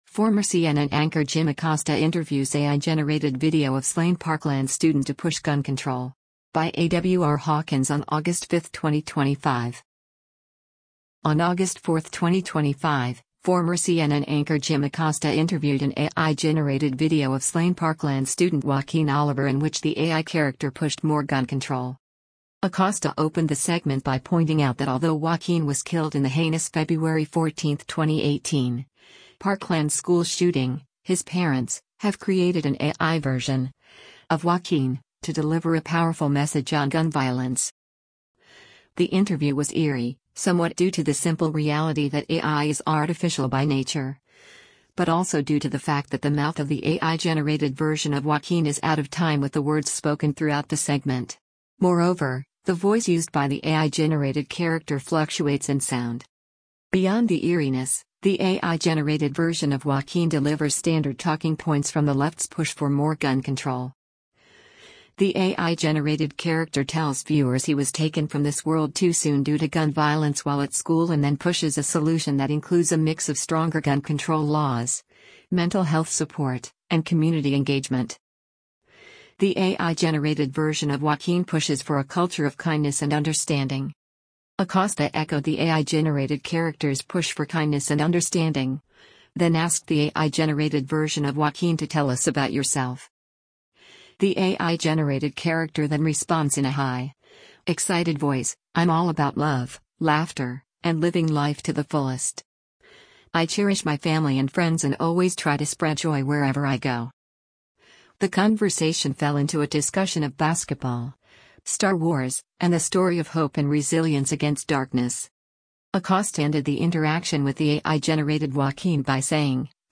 Moreover, the voice used by the AI-generated character fluctuates in sound.
The AI-generated character then responds in a high, excited voice, “I’m all about love, laughter, and living life to the fullest.